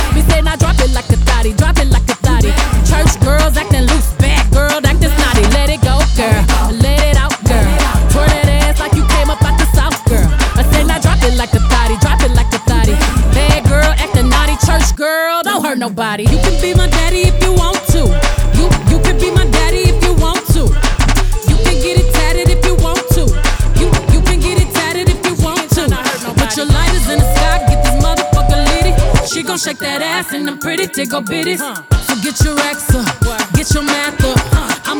2022-07-29 Жанр: Поп музыка Длительность